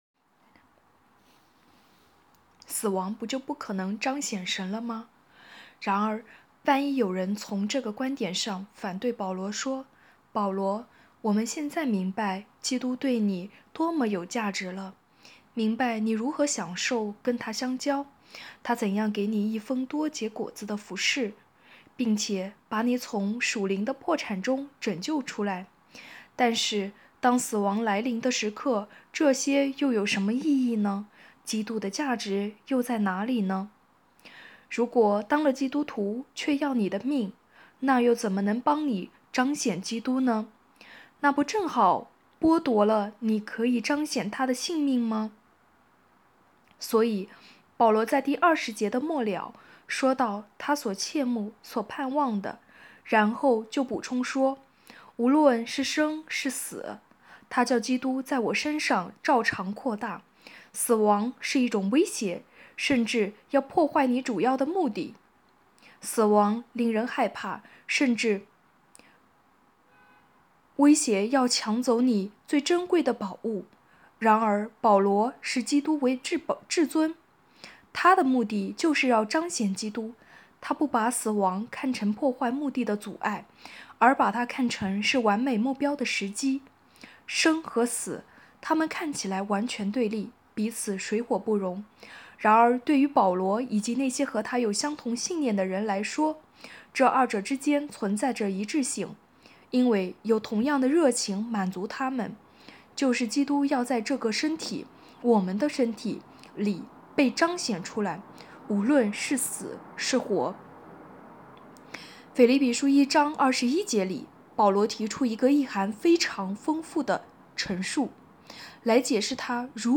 2023年11月23日 “伴你读书”，正在为您朗读：《活出热情》 音频 https